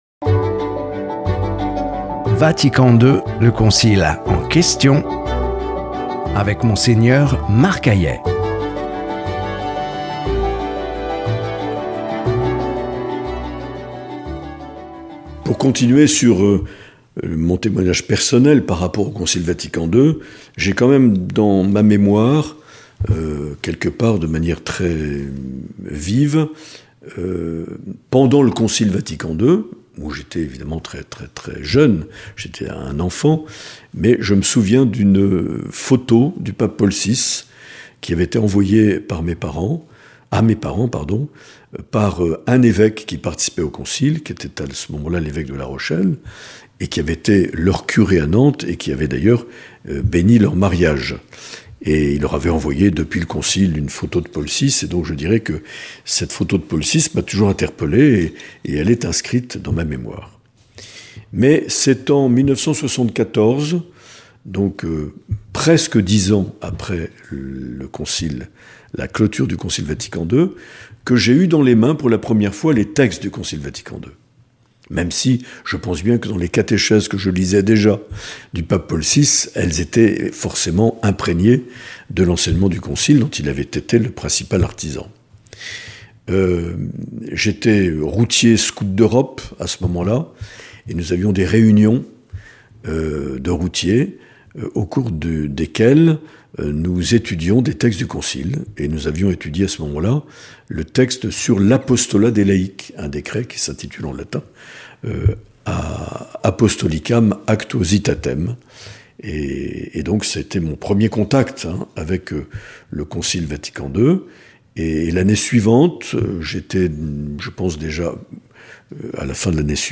3 - Témoignage personnel (suite)
Monseigneur Marc Aillet
Présentateur(trice)